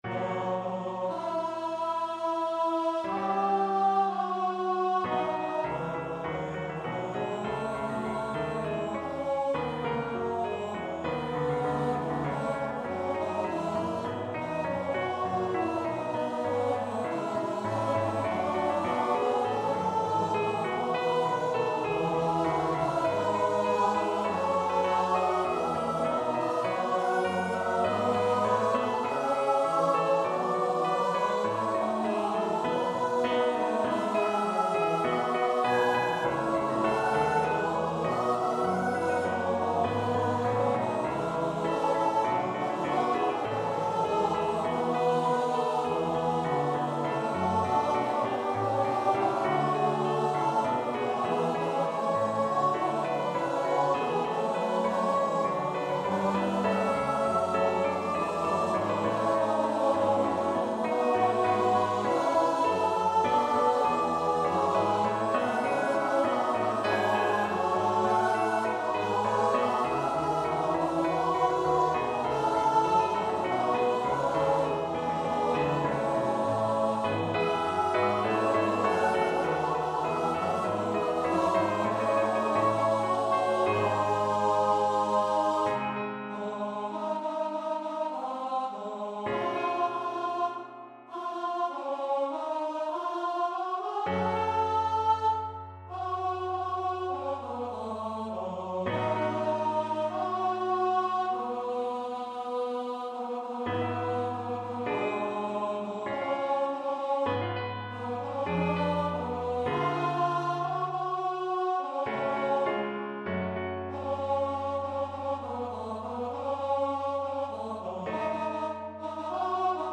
Free Sheet music for Choir (SATB)
Choir  (View more Intermediate Choir Music)
Classical (View more Classical Choir Music)